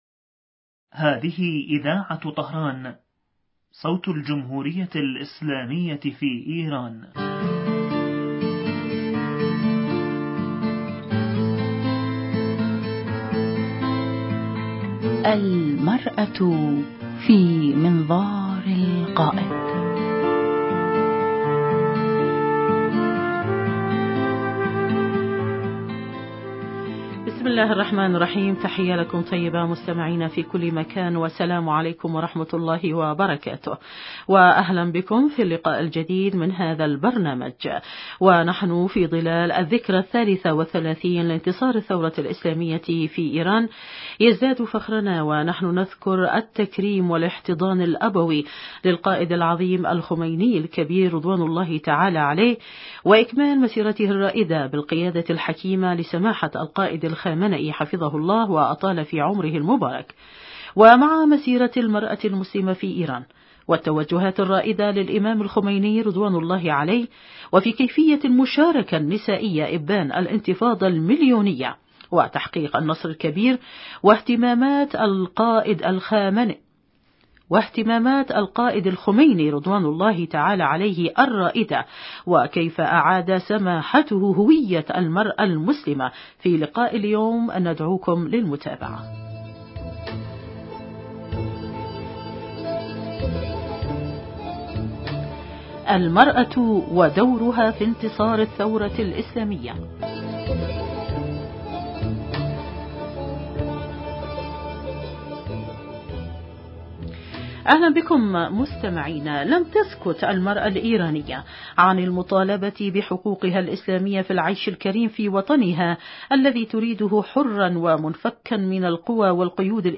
ضيفة البرنامج